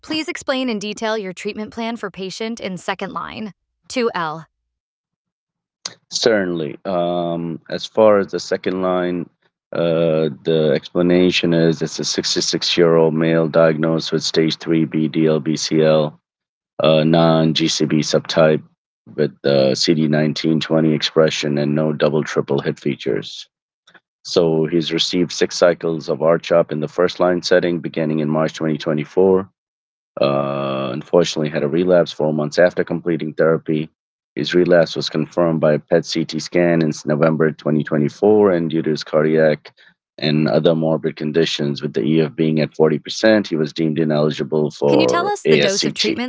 For this pilot study, we recruited 15 hematologist-oncologists to provide detailed patient charts through our conversational patient scribe.